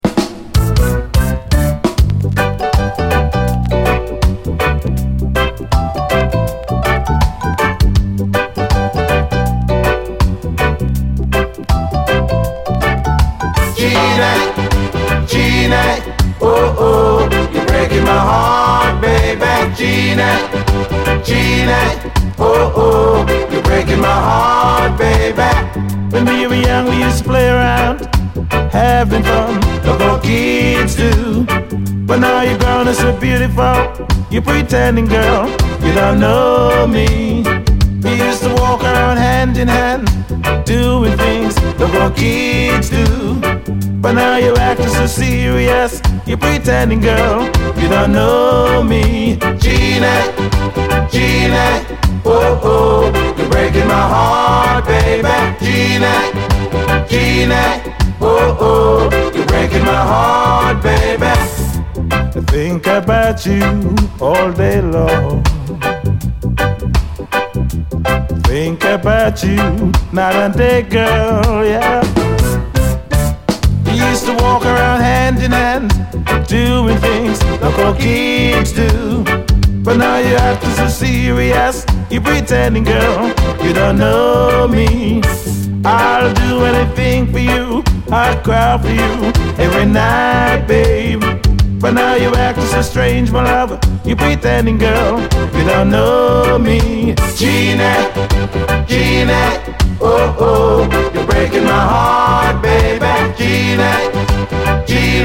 REGGAE, 7INCH
ポップでスウィートなユーロ産黒人白人混成80’Sレゲエ！
黒人白人混成80’Sレゲエ・グループによる、ポップでスウィートなラヴリー・レゲエ！